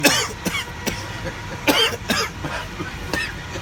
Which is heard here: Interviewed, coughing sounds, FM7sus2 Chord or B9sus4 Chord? coughing sounds